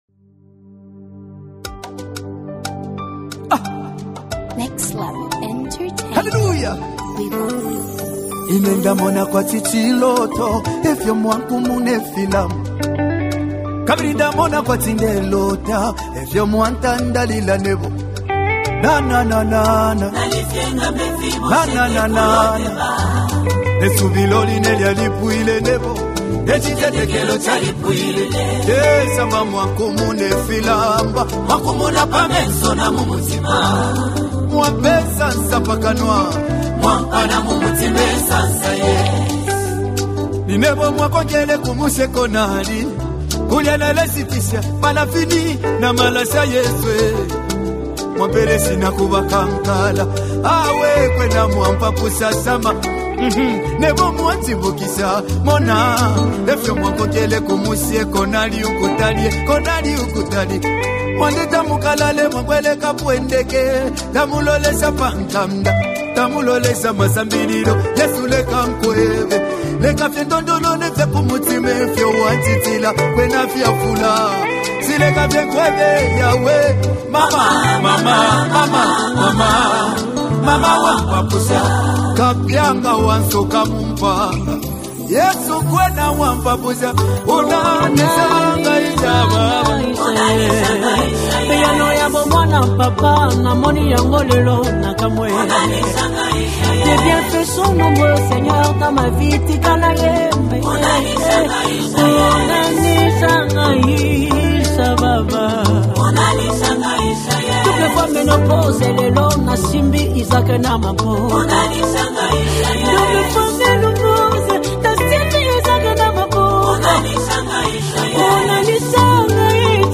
🎼 GENRE: ZAMBIAN GOSPEL MUSIC